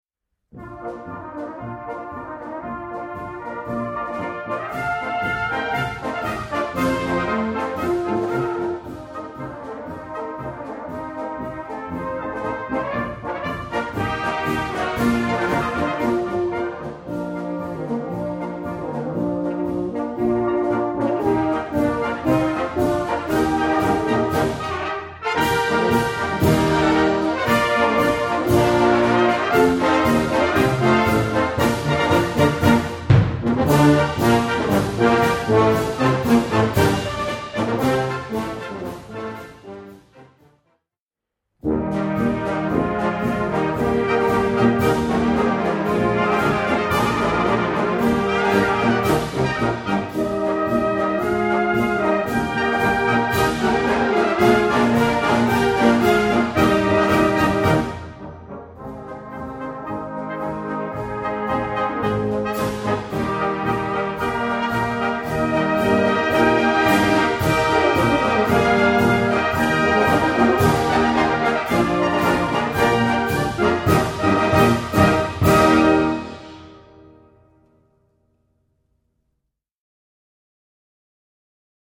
Gattung: Polka
2:45 Minuten Besetzung: Blasorchester Tonprobe